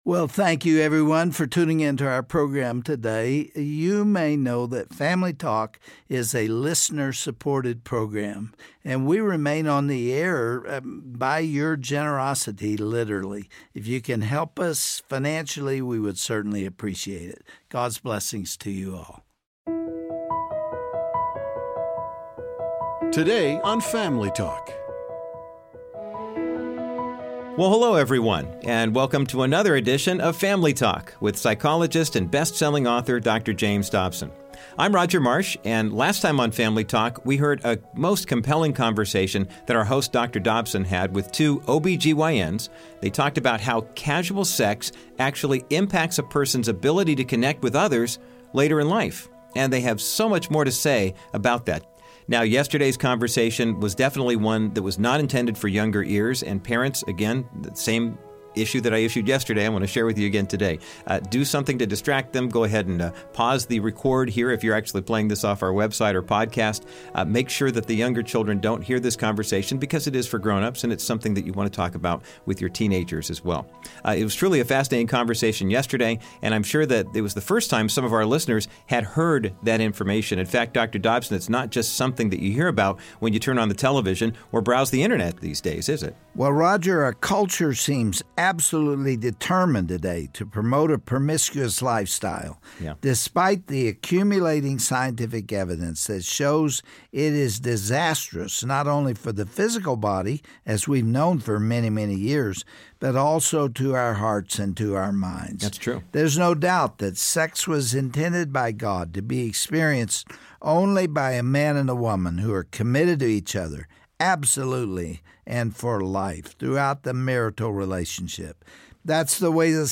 On this broadcast of Family Talk with Dr. James Dobson, two physicians warn that the very same thing occurs in marriage when intimacy has been breached beforehand. It's medical evidence for God's glorious design.